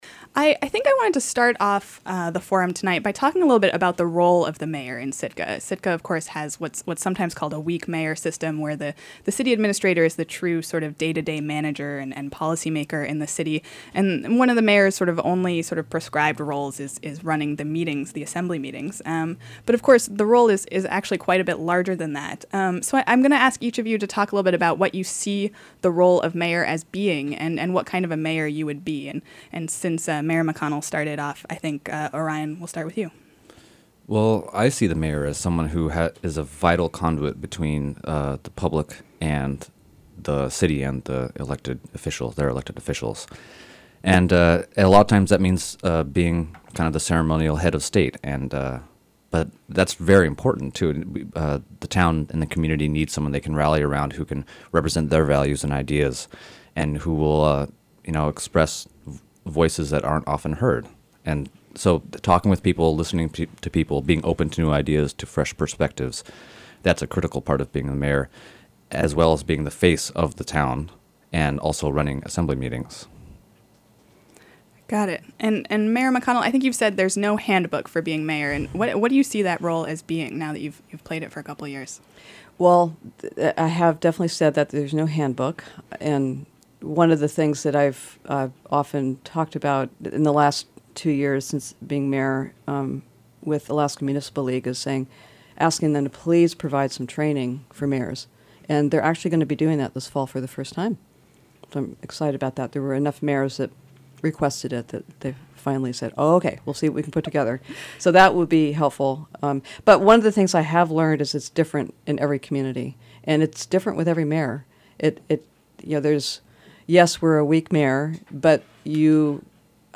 For 90 minutes, they took listener’s question about municipal matters and leadership style.